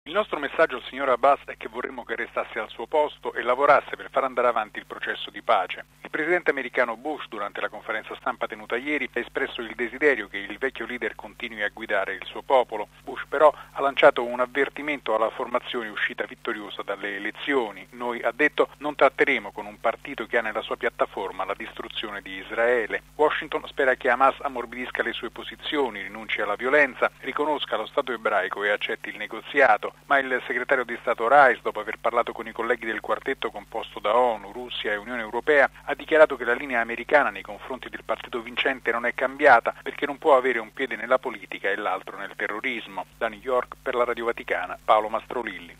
L’Unione Europea affronterà la questione a Bruxelles lunedì prossimo mentre da Washington il presidente Bush chiede al leader palestinese Abu Mazen di continuare a guidare il Paese. Il servizio